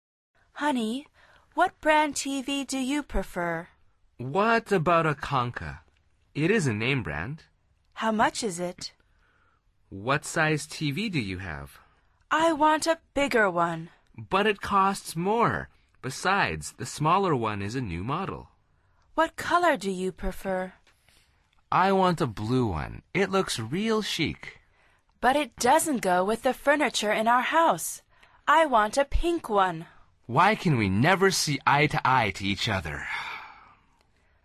Curso Básico de Conversación en Inglés
Al final repite el diálogo en voz alta tratando de imitar la entonación de los locutores.